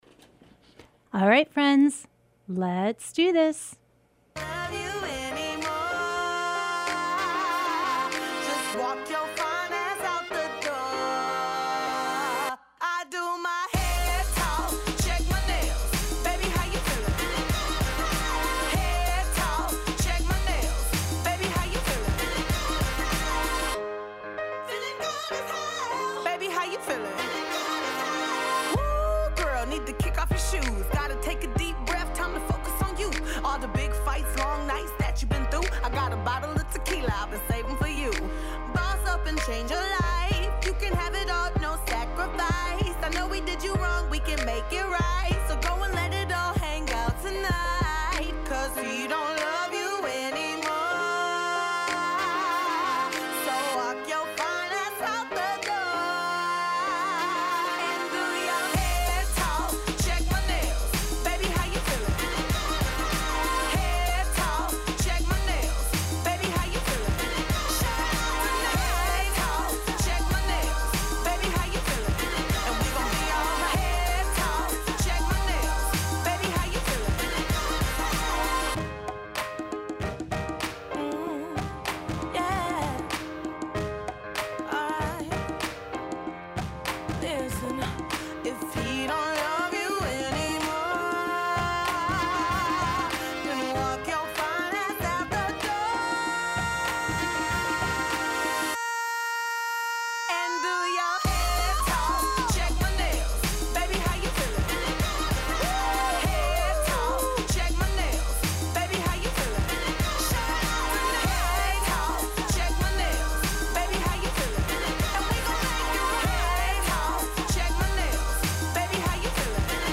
Broadcast live every Thursday evening from 6:30 to 8pm on WTBR